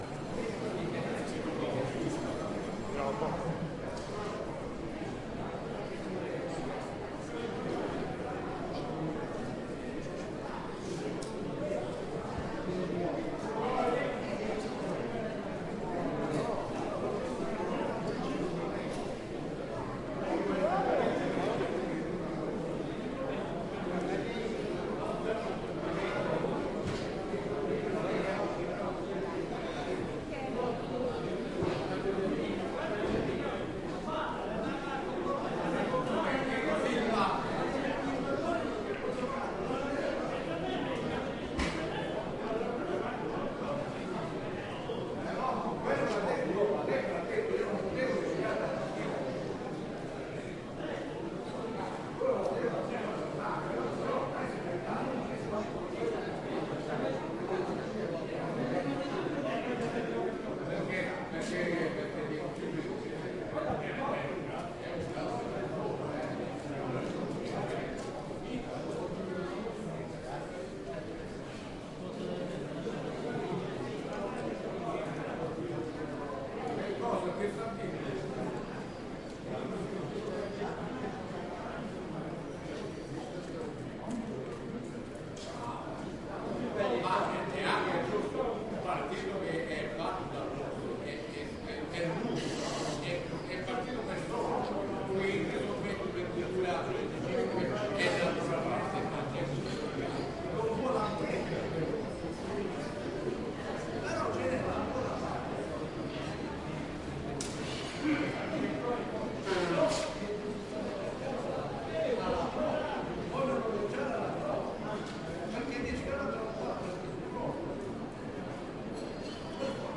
标签： 祈祷 铃铛 喋喋不休 音场 仪式 教堂 宗教 博洛尼亚 现场录音 户外
声道立体声